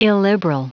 Prononciation du mot illiberal en anglais (fichier audio)
Prononciation du mot : illiberal